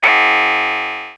ihob/Assets/Extensions/RetroGamesSoundFX/Alert/Alert13.wav at master